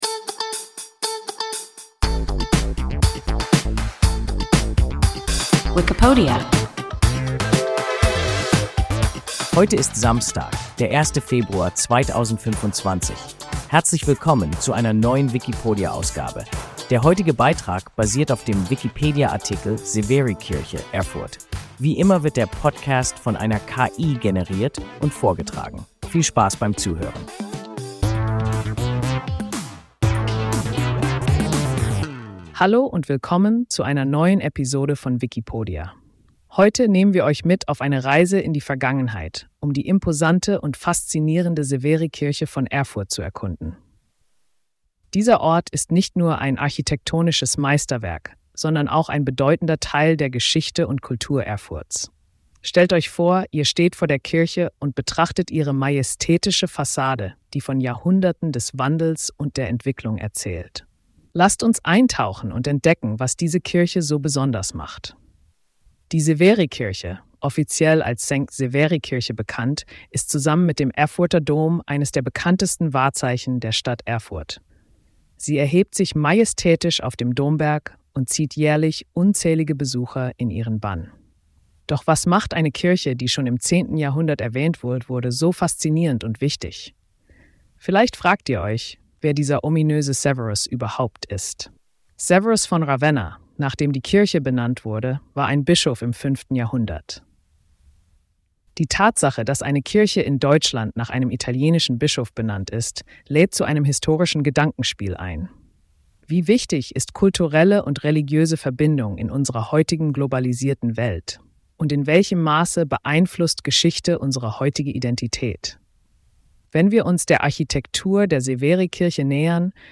Severikirche (Erfurt) – WIKIPODIA – ein KI Podcast